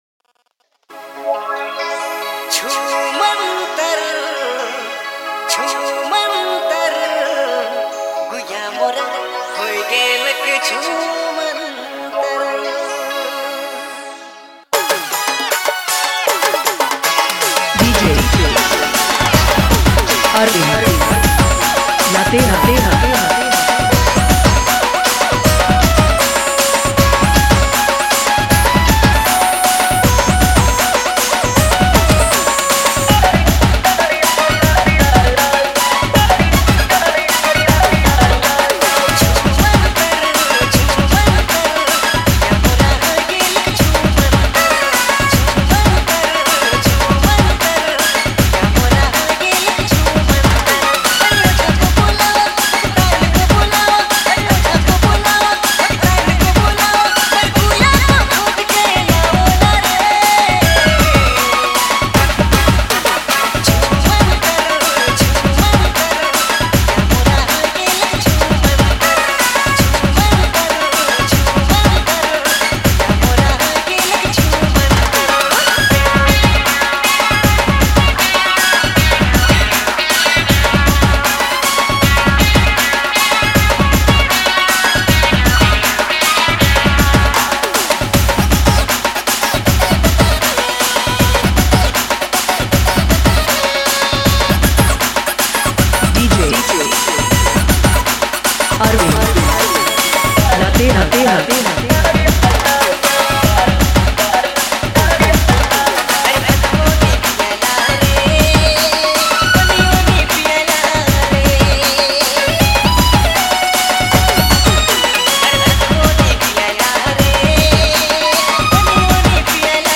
heartfelt Nagpuri song